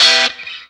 Track 02 - Guitar Stab OS 02.wav